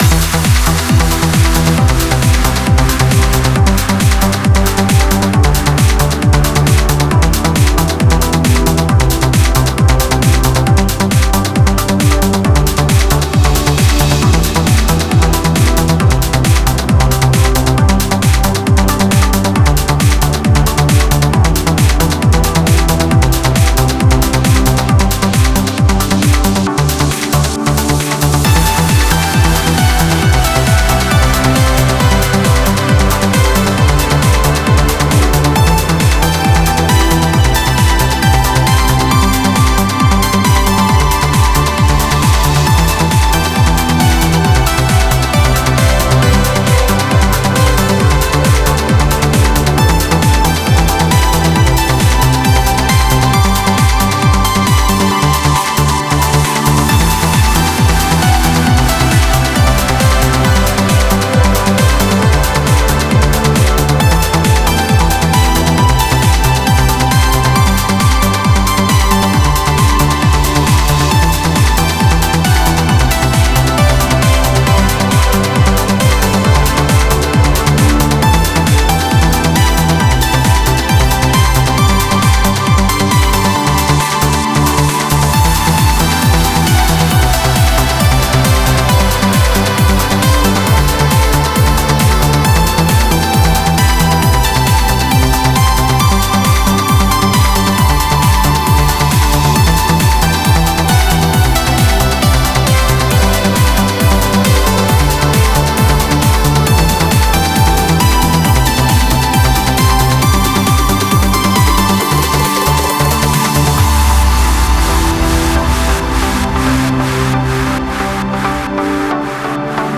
Стиль: Trance